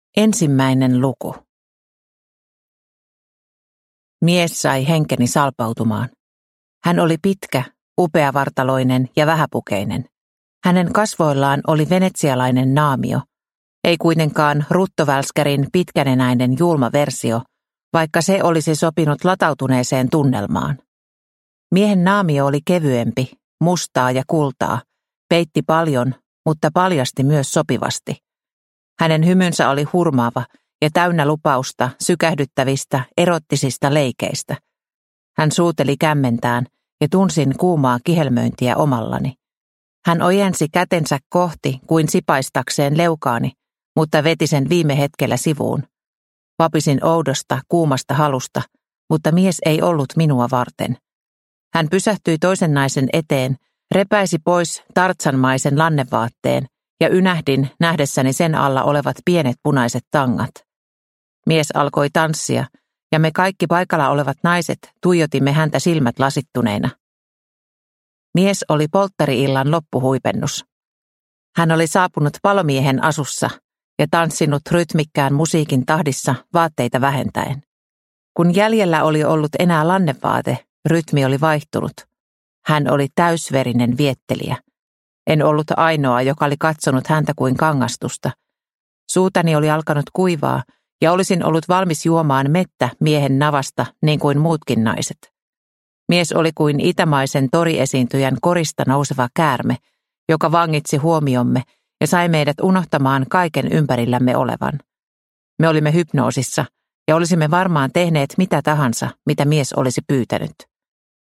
Suklaapolkuja – Ljudbok – Laddas ner